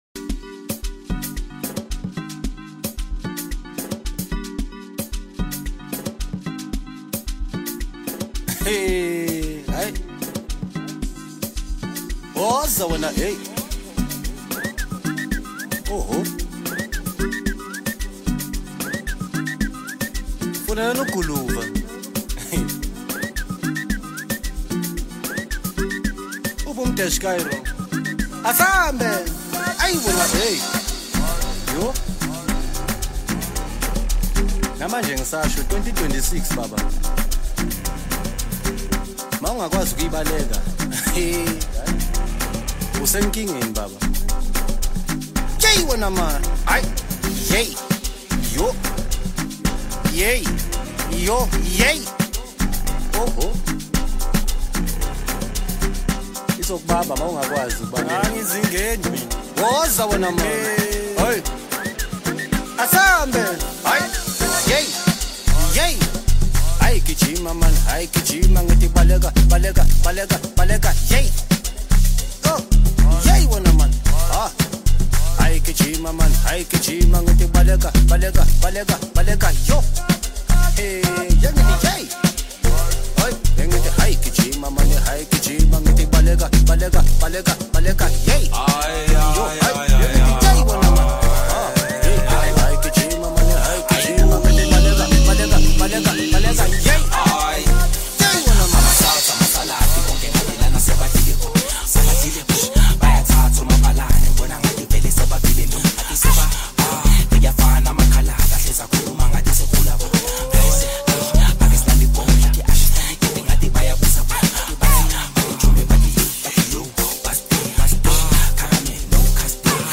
Home » Amapiano » Deep House » Hip Hop » Latest Mix
Talented vocalist
catchy rhythm, smooth vibe, and strong replay appeal